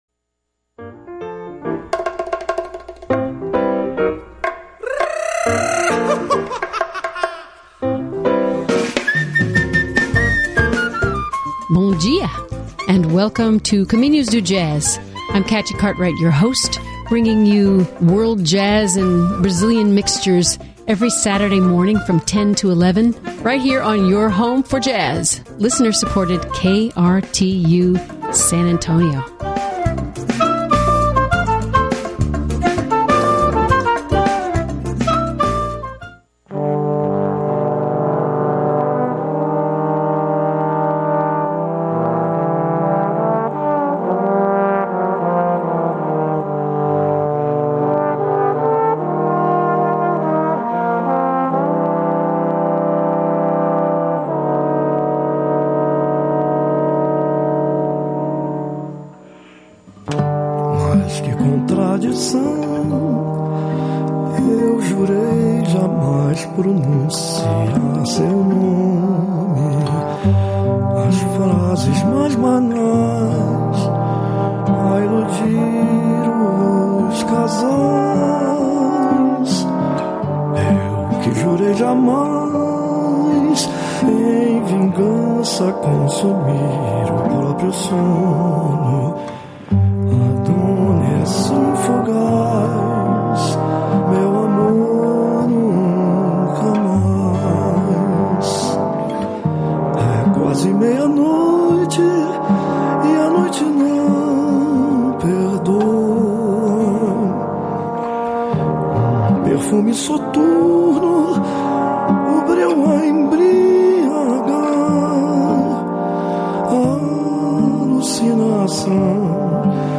trombonist
guitarist